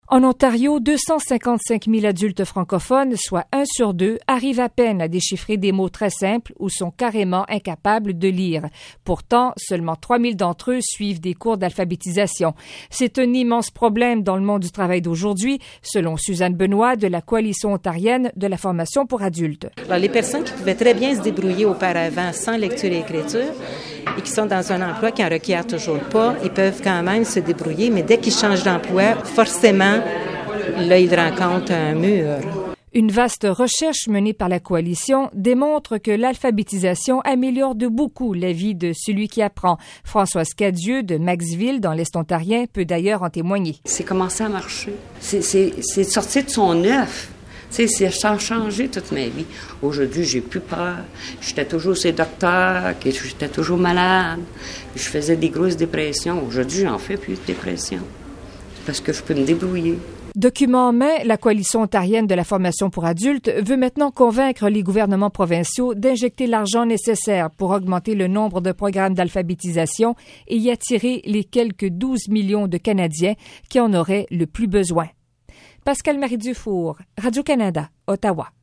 Entrevue à la radio